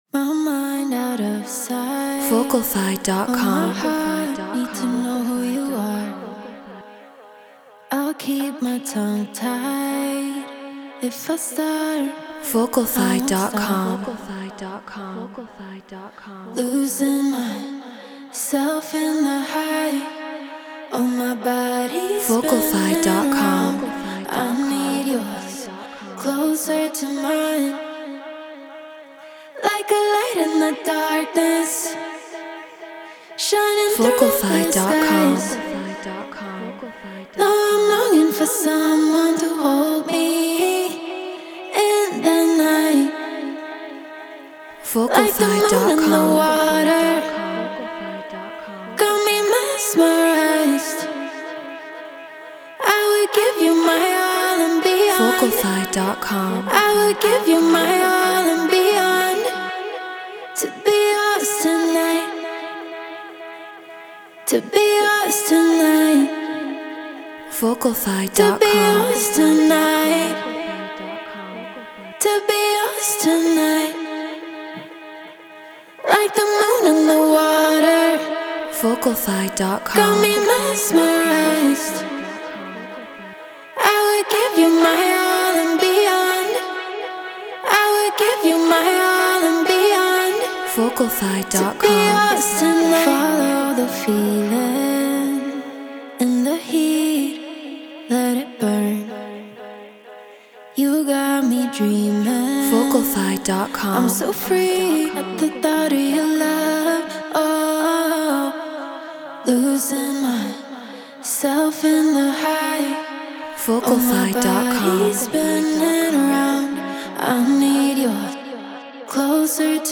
House 124 BPM Bmaj
Shure KSM 44 Apollo Twin X Pro Tools Treated Room